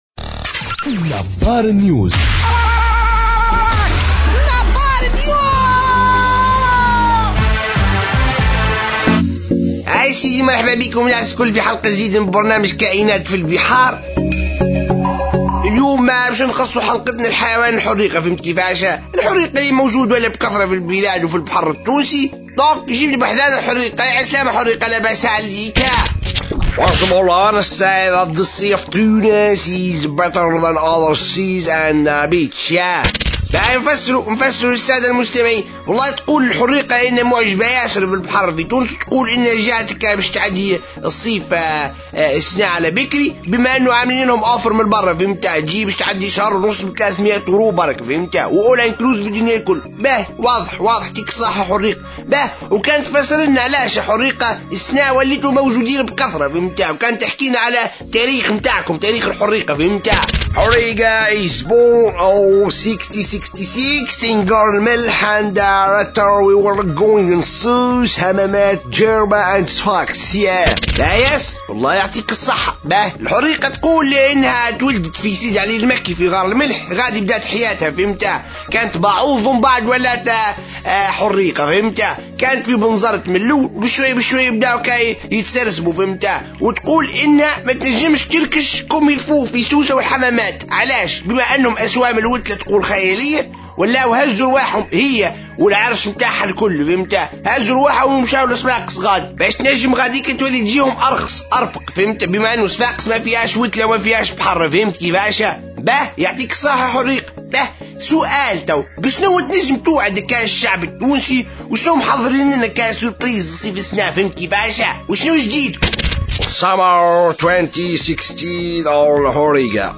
Interview avec un incendie